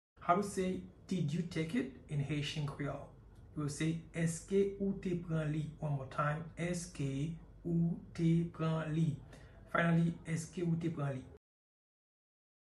Èske ou te pran li?” Pronunciation in Haitian Creole by a native Haitian can be heard in the audio here or in the video below:
Did-you-take-it-in-Haitian-Creole-Eske-ou-te-pran-li-pronunciation-by-a-Haitian-teacher.mp3